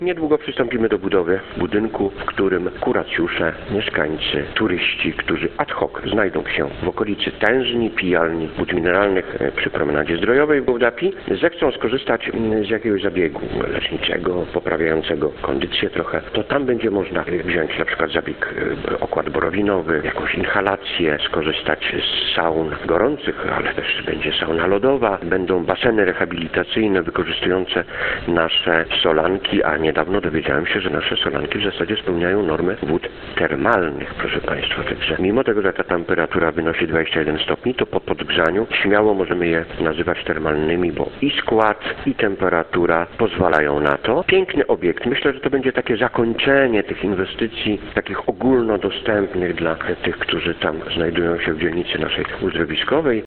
Mówi Jacek Morzy, zastępca burmistrza Gołdapi.